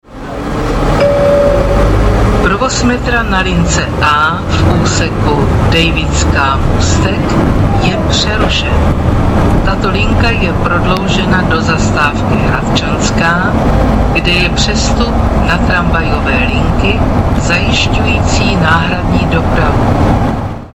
- Hlášení o výluce v autobusech si